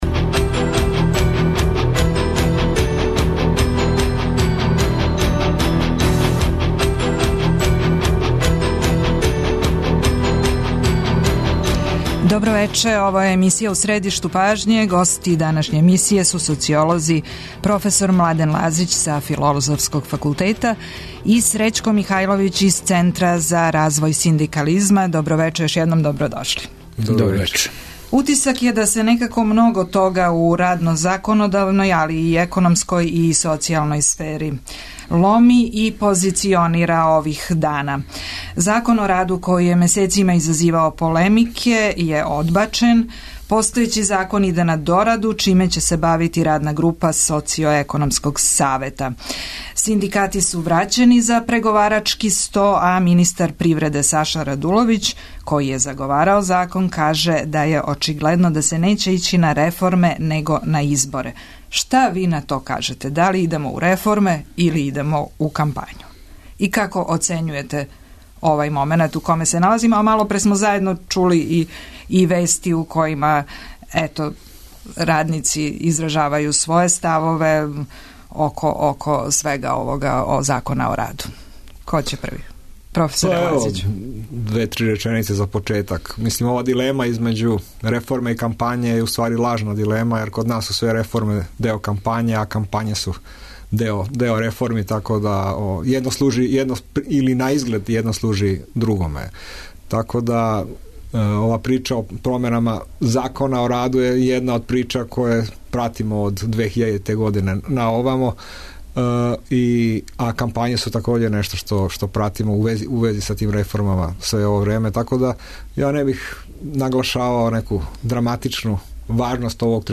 доноси интервју са нашим најбољим аналитичарима и коментаторима, политичарима и експертима